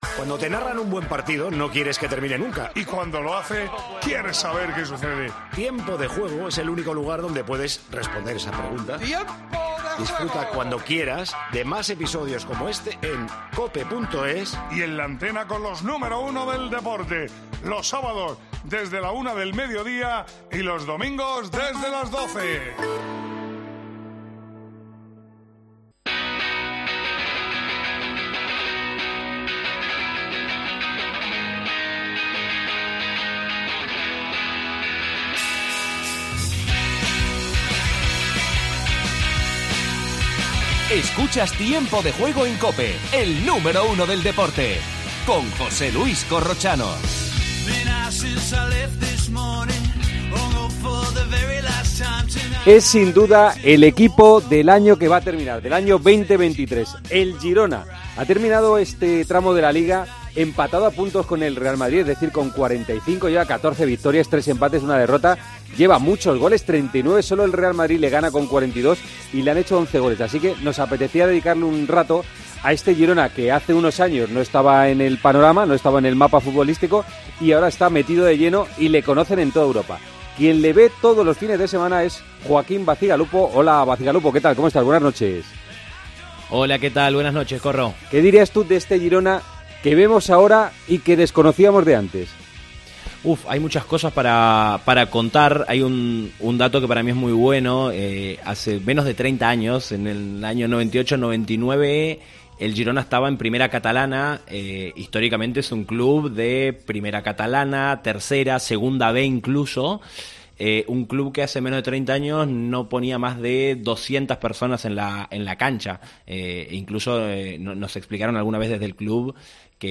Entrevista a Gorka Iraizoz (exportero del Girona).